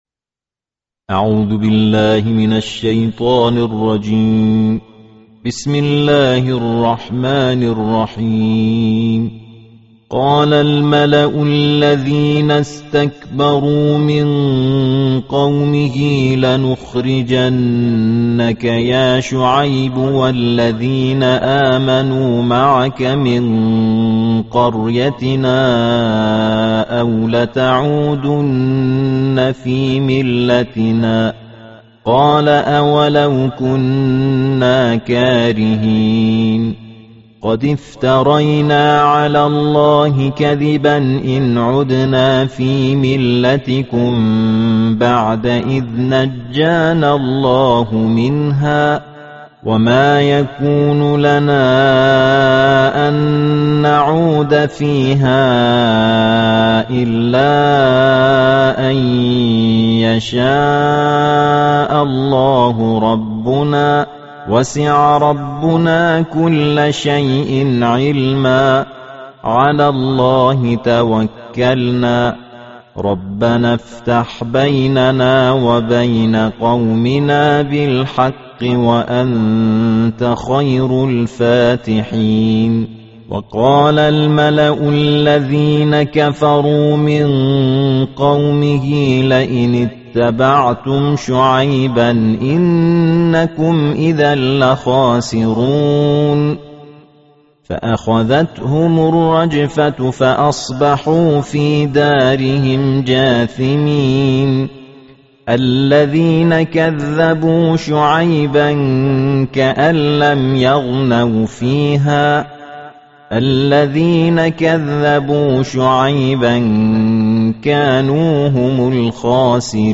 Bacaan Tartil Juzuk Kesembilan Al-Quran